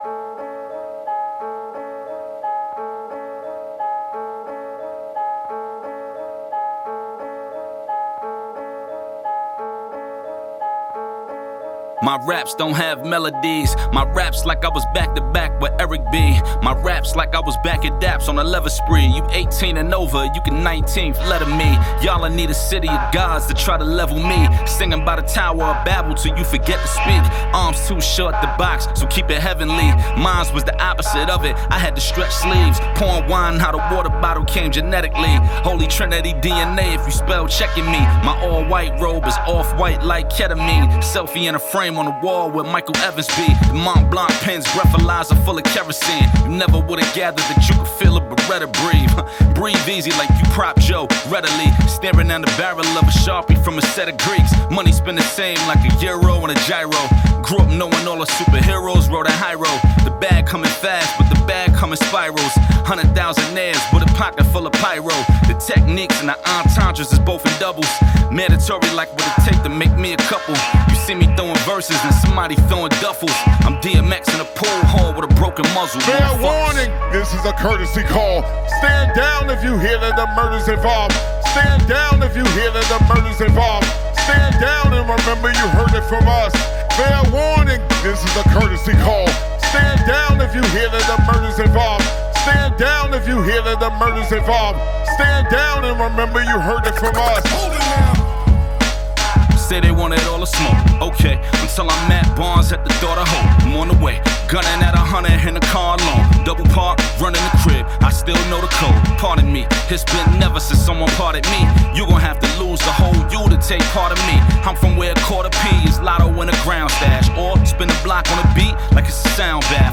Genre: Hip-Hop.